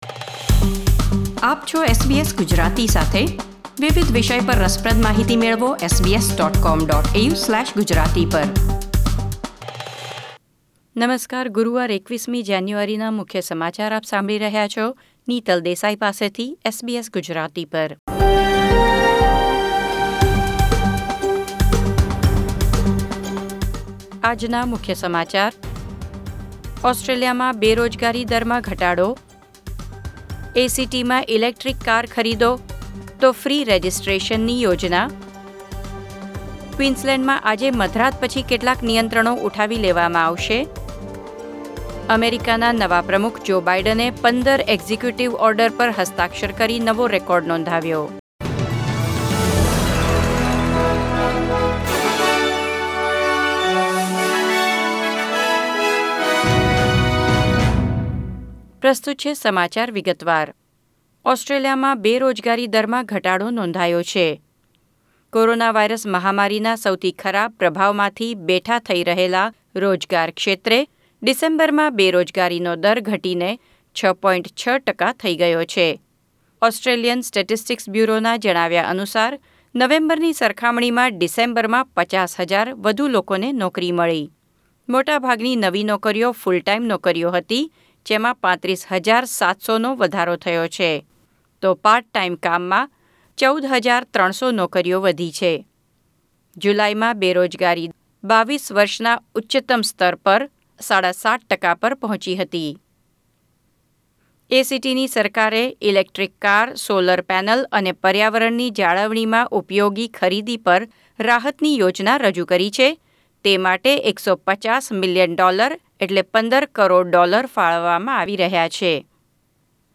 SBS Gujarati News Bulletin 21 January 2021